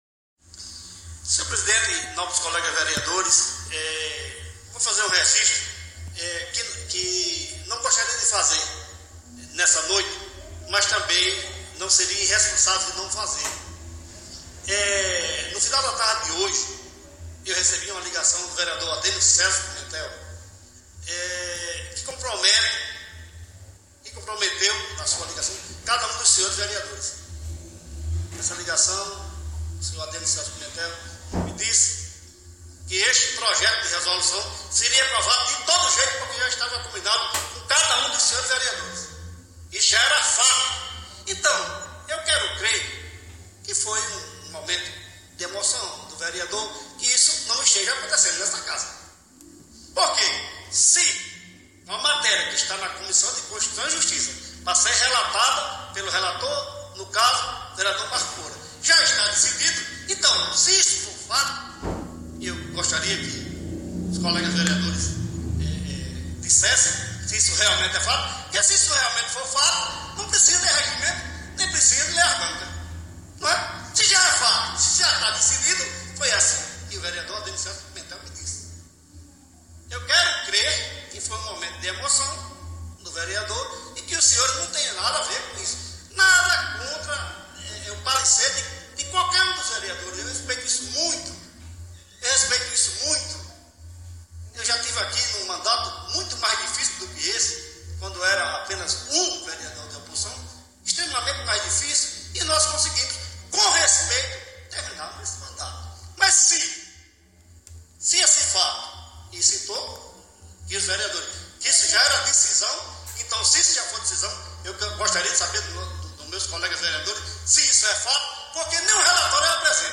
Vereador fez revelação na tribuna da Câmara dos Vereadores
Escute o áudio da fala do vereador Marcos Moura abaixo, que foi veiculado nesta sexta-feira (6), na rádio Interação FM: